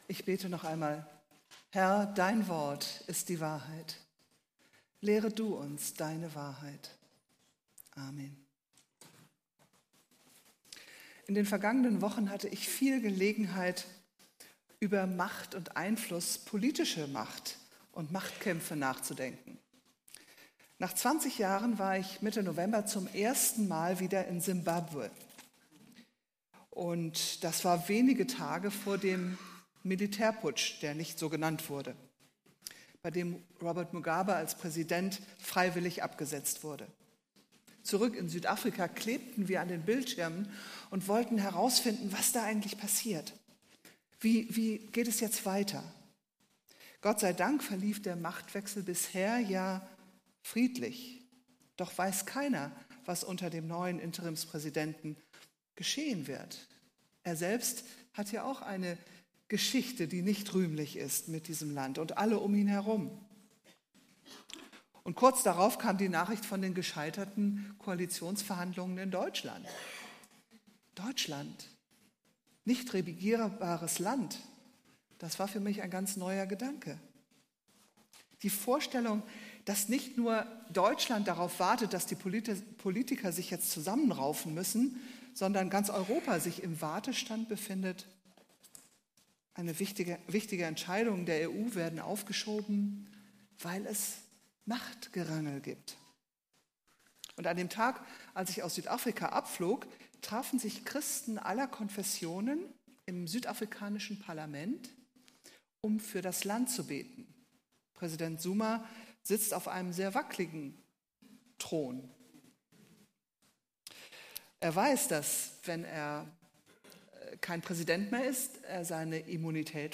Predigttext: Apostelgeschichte 3, 1-10